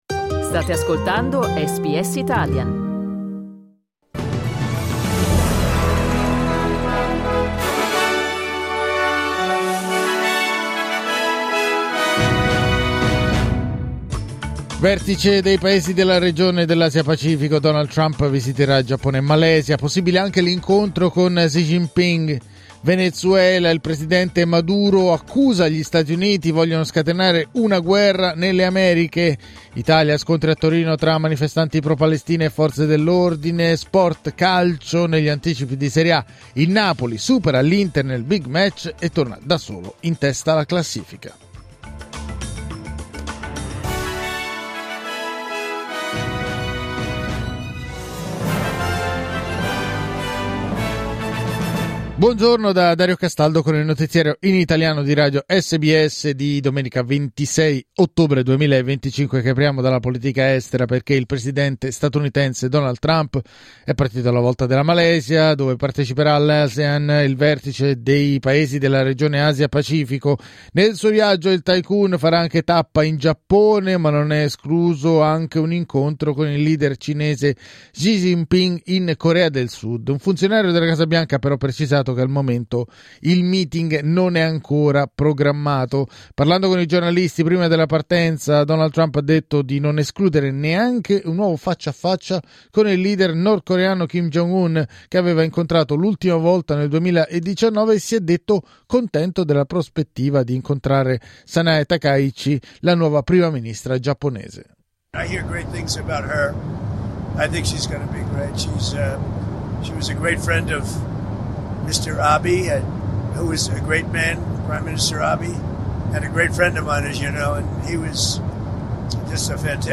Giornale radio domenica 26 ottobre 2025